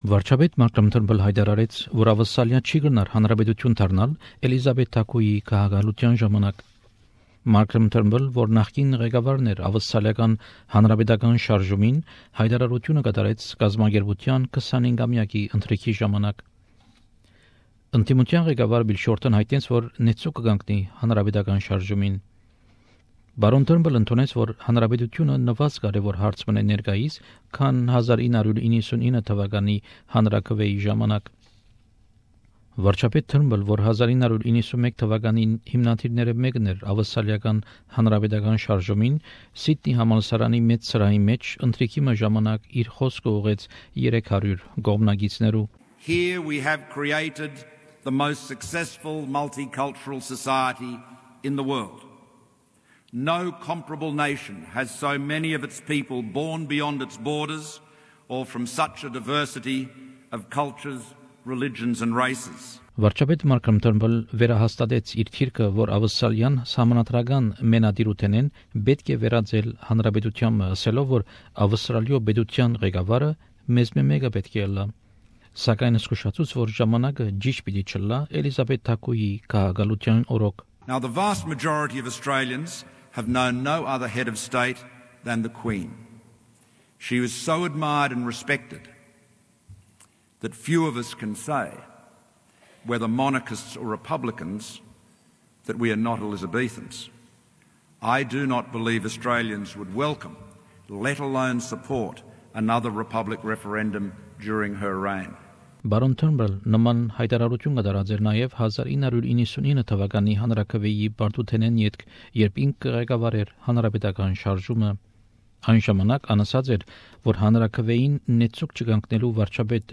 Prime Minister Malcolm Turnbull addressing the Australian Republican Movement’s 25th anniversary dinner in Sydney Source: AAP